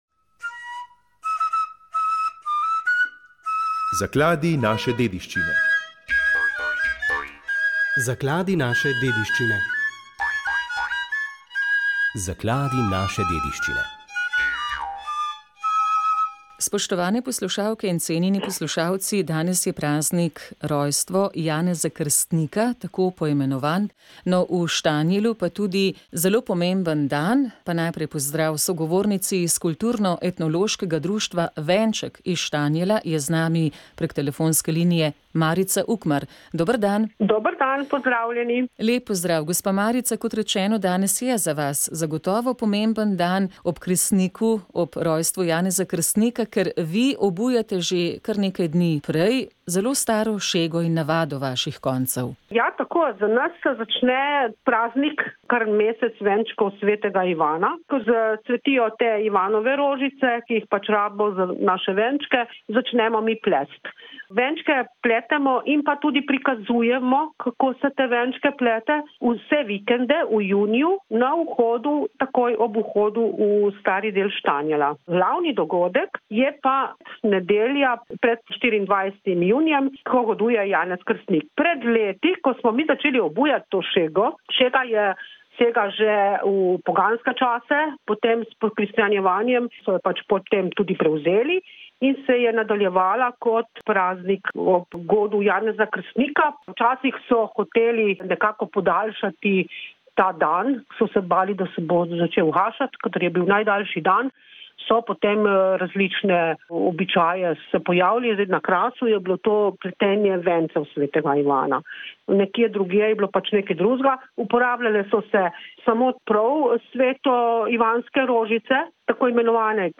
Ob 60. obletnici smrti škofa Antona Vovka smo pogovor posvetili njegovi predanosti Cerkvi v Sloveniji in slovenskemu narodu.